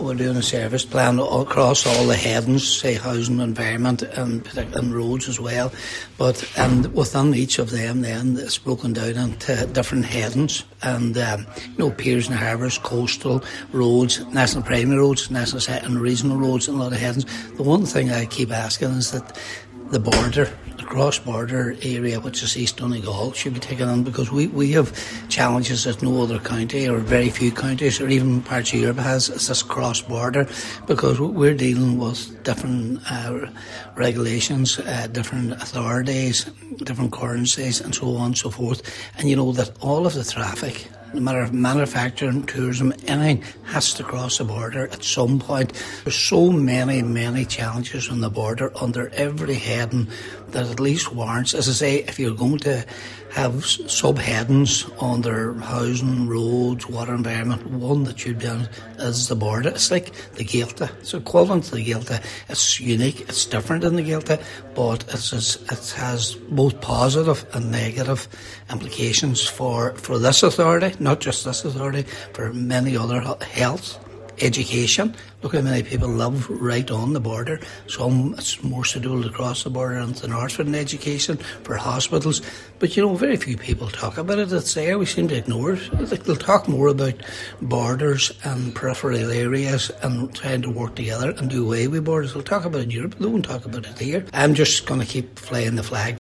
Speaking at the meeting, Cllr Patrick McGowan said the border area in East Donegal should receive its own heading in the plan.